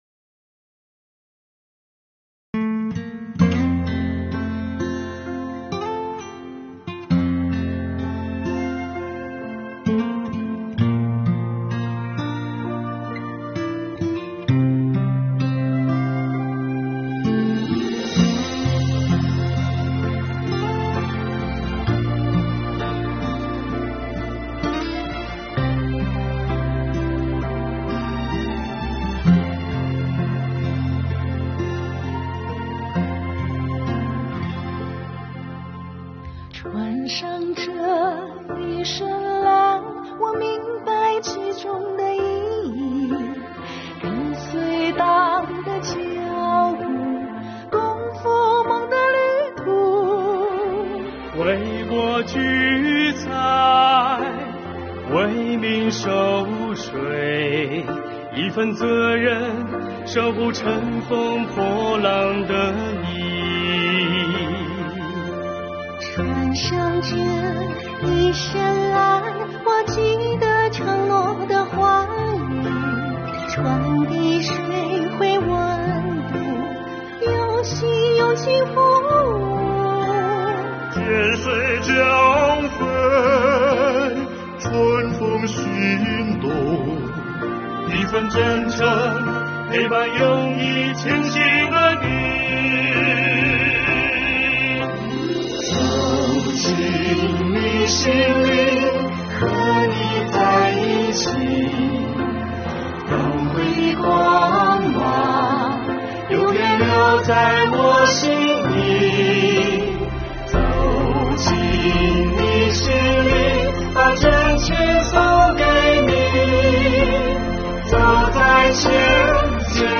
原创MV《走进你心里》由广东江门税务干部作词、演唱，诉说着税务人真诚守护、用心服务的真挚情感。
MV歌词走心带着丝丝暖意，旋律亲切饱含真挚情感，加上一幕幕税务干部用心用情服务的动人场景，呈现出一幅幅活力迸发、昂扬向上的生动画面，感染力强，让人产生共鸣。